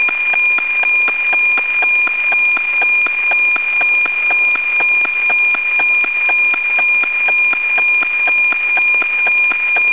Both use a nominal 2400 Hz AM subcarrier.
The format used by the U.S. NOAA satellites is 240 lines per minute, with alternating visible and infrared scans. This sample is from NOAA-14.
The "tick-tock" effect is the sync pulses. The beginning of the visible scan is a burst of 1040 Hz ("tick"), while the beginning of the IR scan is 832 Hz ("tock").